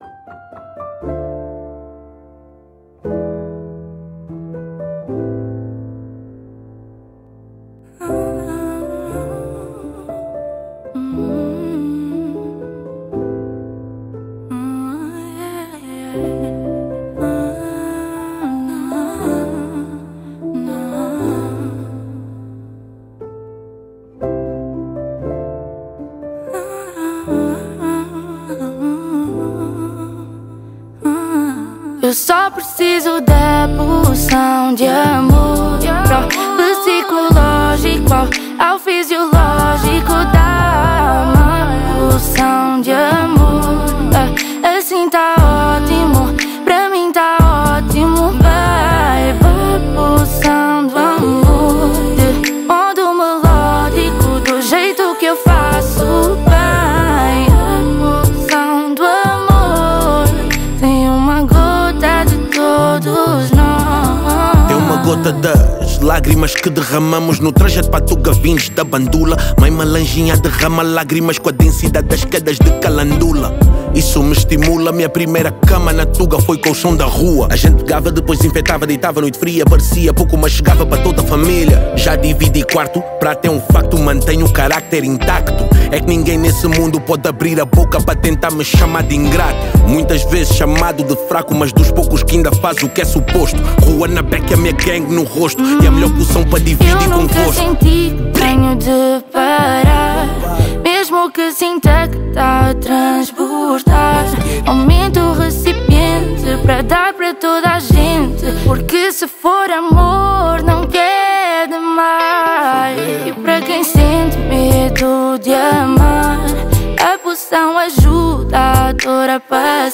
Genero: R&B/Soul